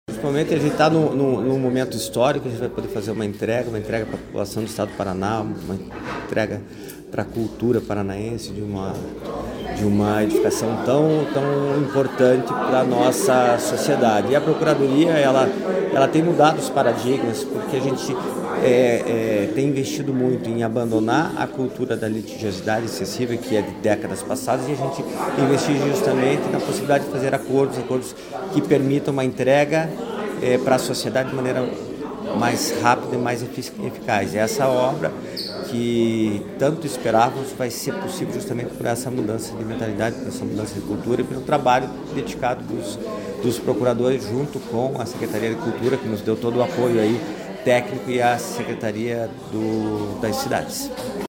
Sonora do procurador-geral do Estado, Luciano Borges, sobre o acordo para retomada das obras do Museu de Arte Contemporânea